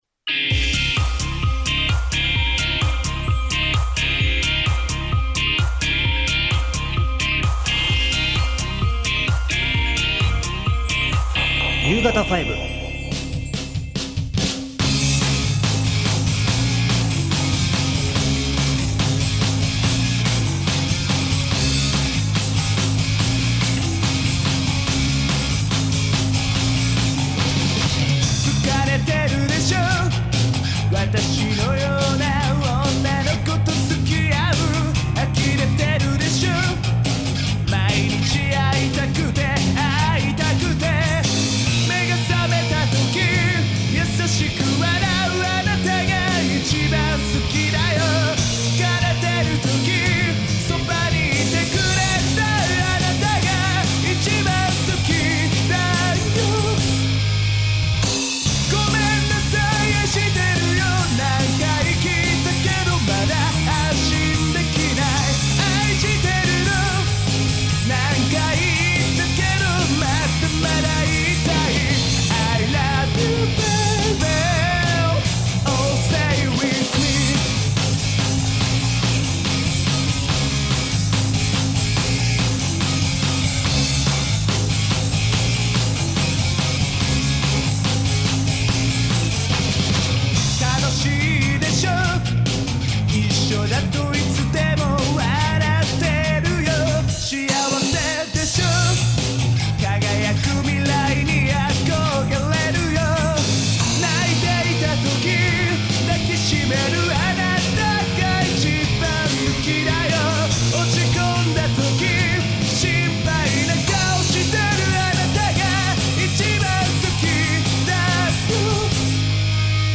今回の放送同録音源はこちら↓